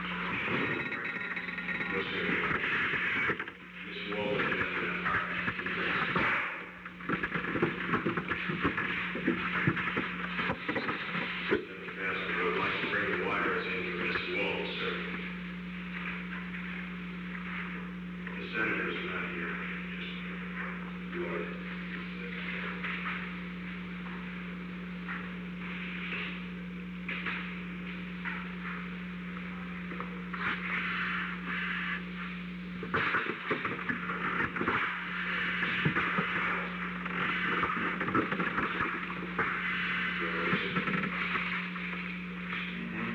Secret White House Tapes
Conversation No. 607-15
Location: Oval Office